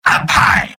Robot-filtered lines from MvM. This is an audio clip from the game Team Fortress 2 .
{{AudioTF2}} Category:Spy Robot audio responses You cannot overwrite this file.